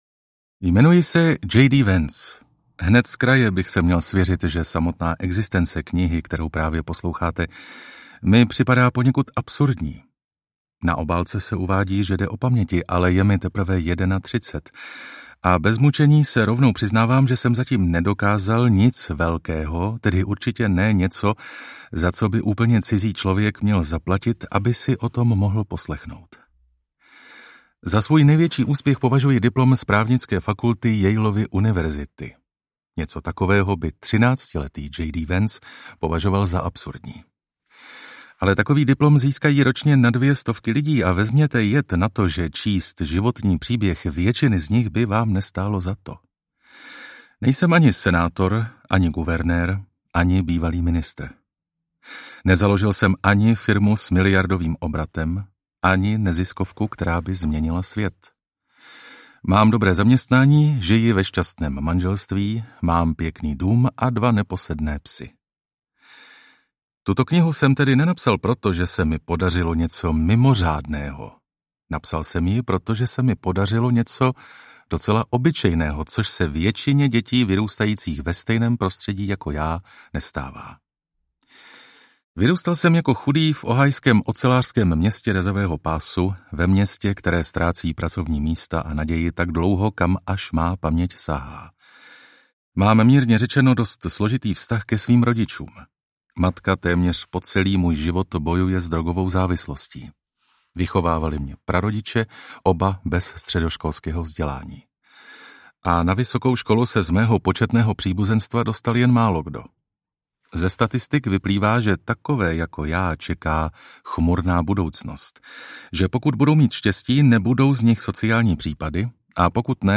Americká elegie: Memoáry o krizi rodiny a kultury audiokniha
Ukázka z knihy